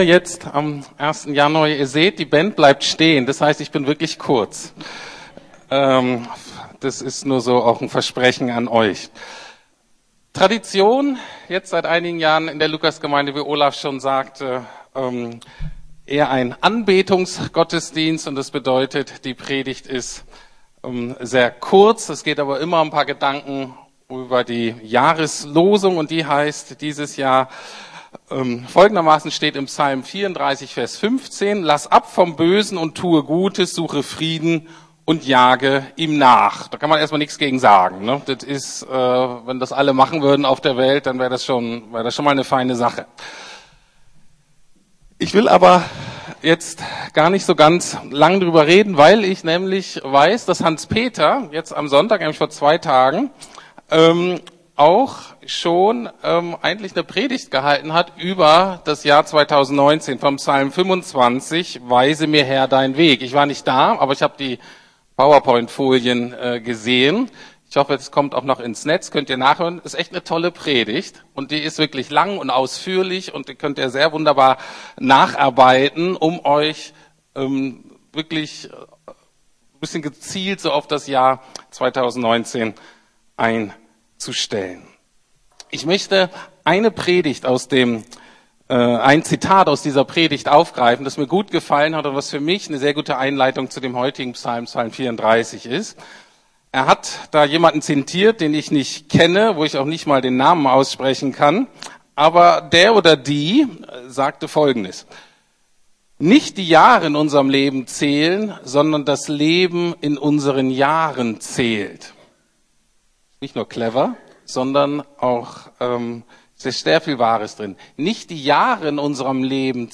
Neujahrgottesdienst